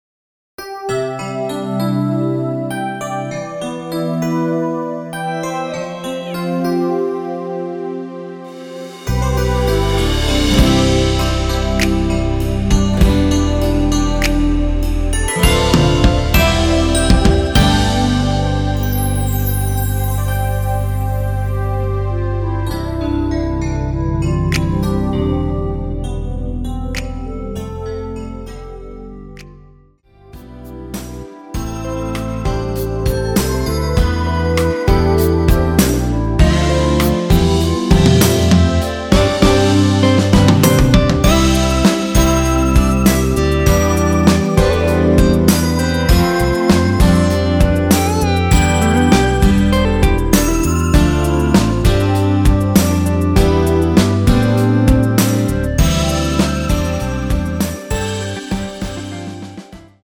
원키에서(-2)내린 멜로디 포함된 MR입니다.
멜로디 MR이라고 합니다.
앞부분30초, 뒷부분30초씩 편집해서 올려 드리고 있습니다.
중간에 음이 끈어지고 다시 나오는 이유는